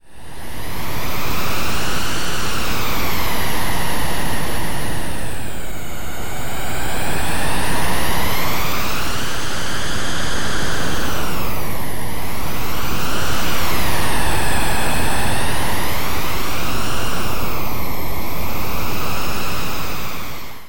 StormNoise  patch
ModEntropy_StormNoise.mp3